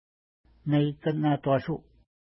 Pronunciation: meikən-na:twa:ʃu:
Pronunciation